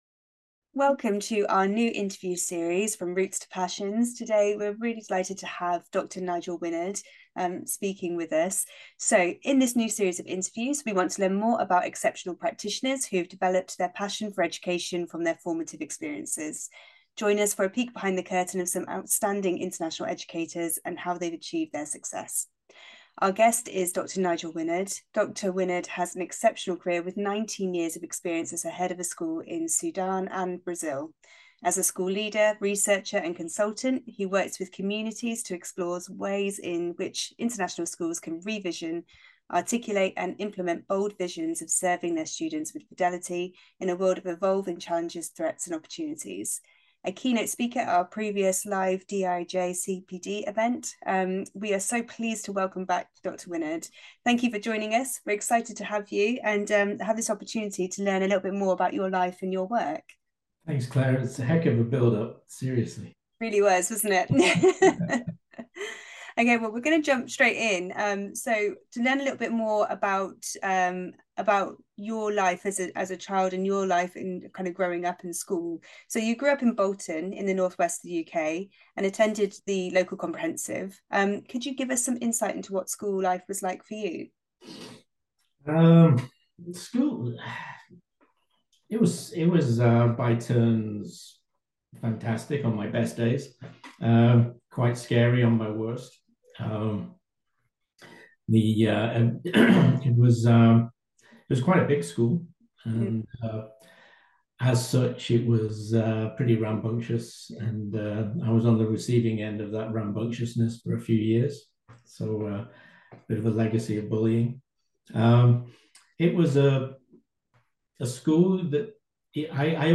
In this new series of interviews, we want to learn more about exceptional practitioners who have developed their passion for education from their formative experiences.